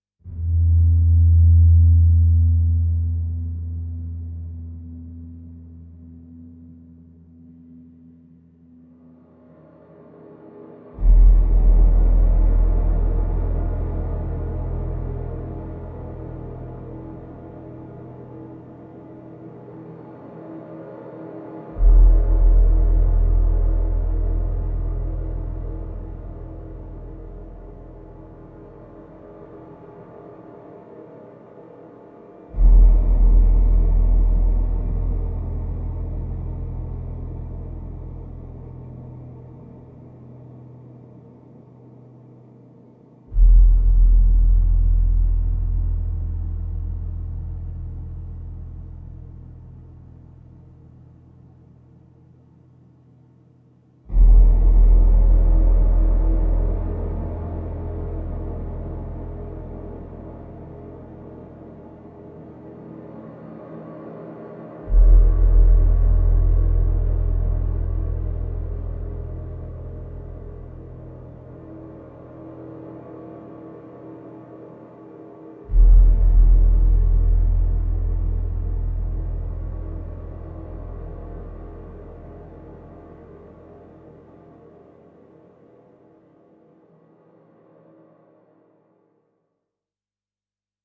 Dark, menacing synth swells create an ominous ambience.